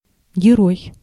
Ääntäminen
IPA: [gʲɪˈroj]